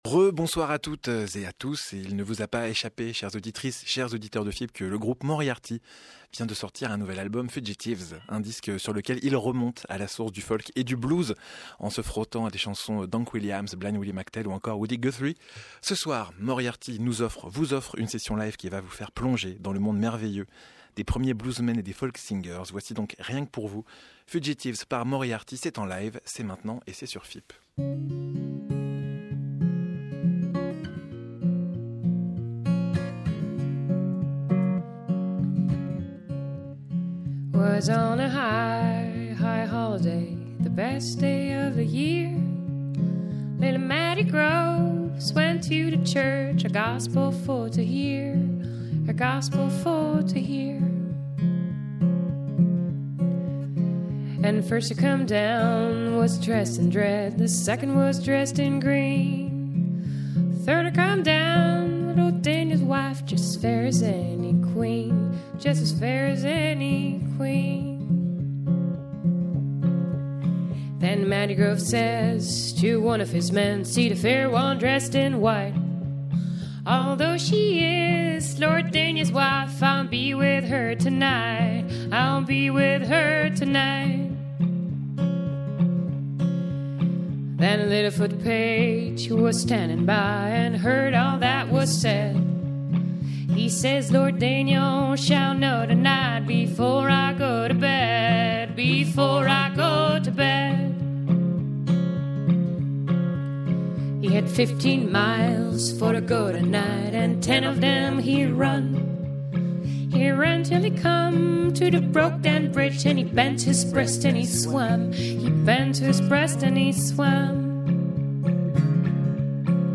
a mashup of styles and countries
American Roots music
haunting vocals